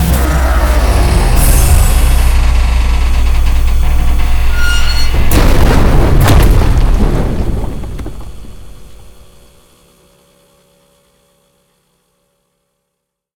die.ogg